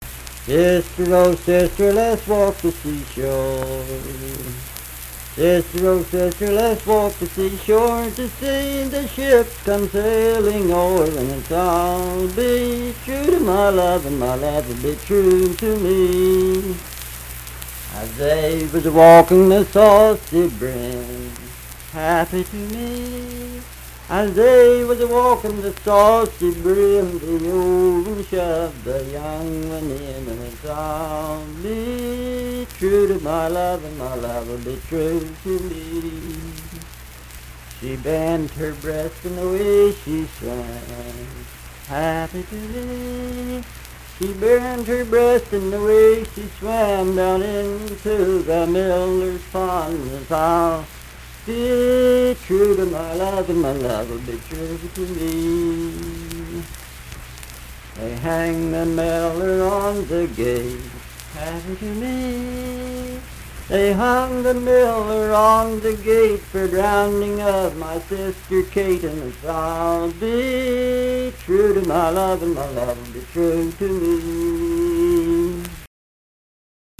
Unaccompanied vocal music
Verse-refrain 4(5w/R).
Voice (sung)